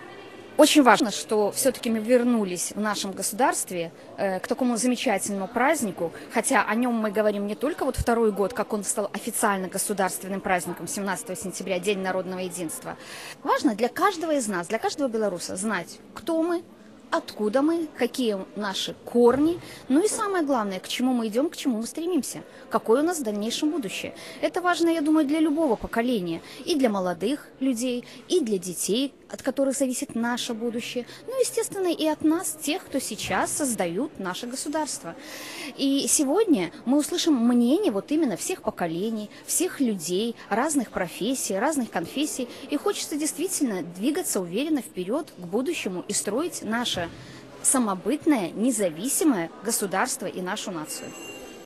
Масштабное мероприятие под девизом «Единство. Истина. Будущее» с участием делегации нашего города, Барановичского, Ляховичского, Ивацевичского, Ганцевичского районов состоялось на базе Барановичского государственного университета.